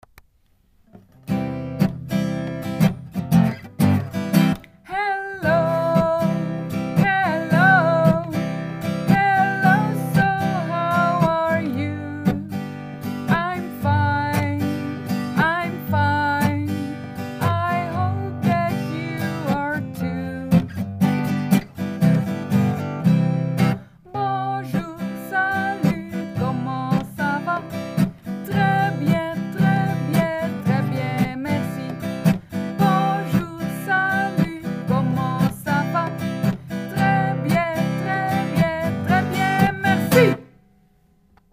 openings- en slotliedjes van de muziekles